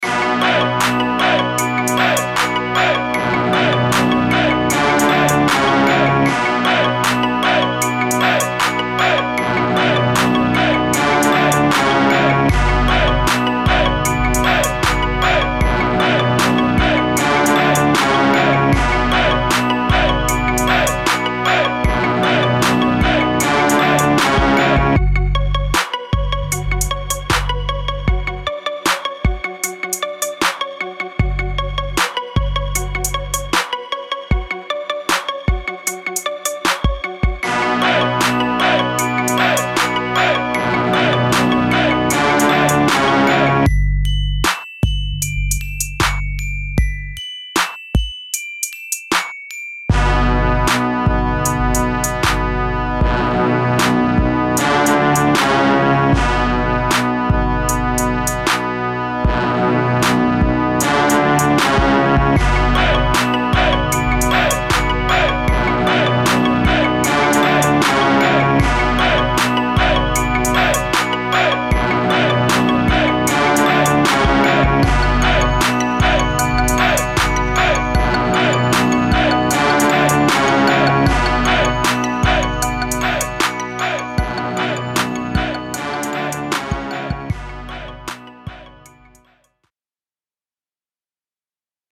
This is a down south crunk party track that is 77 bpm